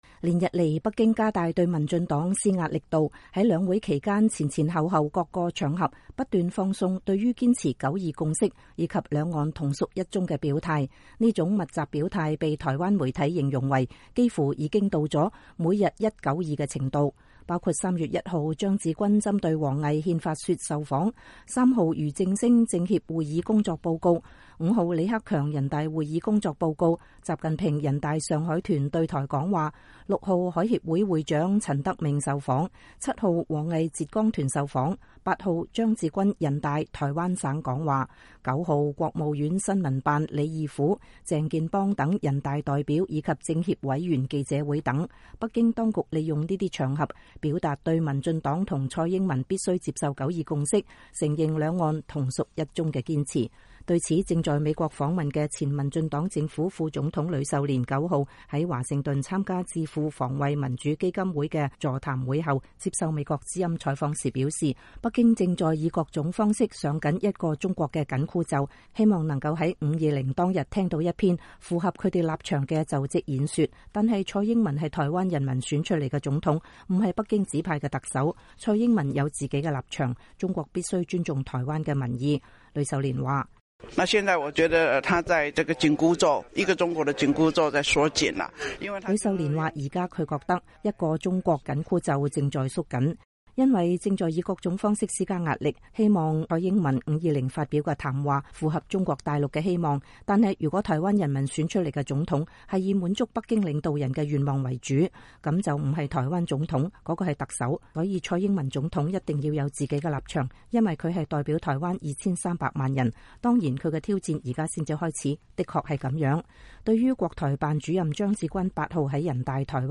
對此，正在美國訪問的前民進黨政府副總統呂秀蓮9日在華盛頓參加智庫防衛民主基金會的座談會後接受美國之音採訪時表示，北京正在以各種方式上緊“一個中國”的緊箍咒，希望能在520當天聽到一篇符合他們立場的就職演說，但蔡英文是台灣人民選出來的總統，不是北京指派的特首，蔡英文有自己的立場，中國必須尊重台灣的民意。